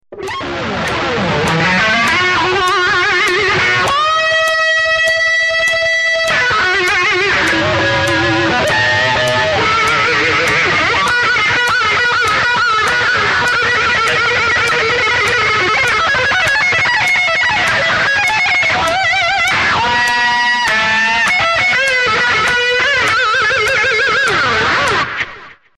Други, вот пересвёл и добавил 3 трека альтов(2х4 чамберов и один сольный), дабы не было "левого" перекоса, по возможности учел замечания...:scaut_en: Слуханите еще разок, пжлста...